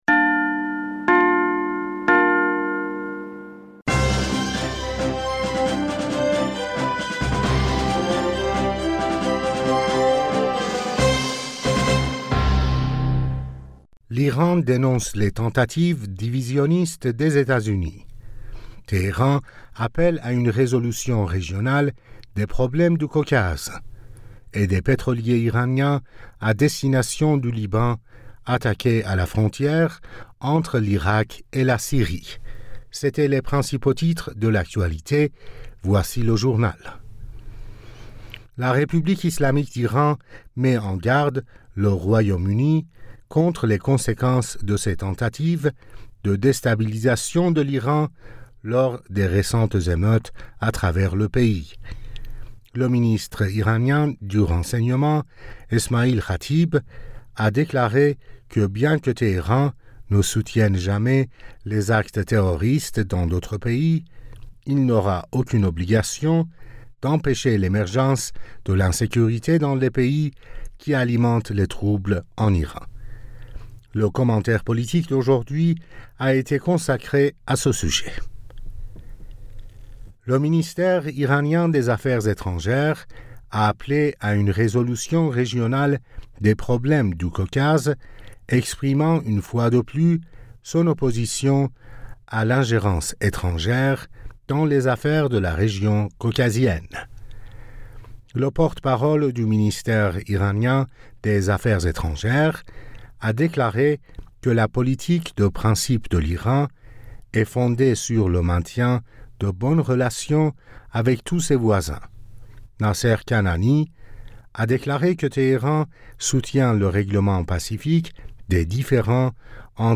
Bulletin d'information Du 09 Novembre